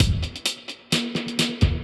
Index of /musicradar/dub-designer-samples/130bpm/Beats
DD_BeatB_130-03.wav